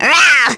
Nia-Vox_Attack2.wav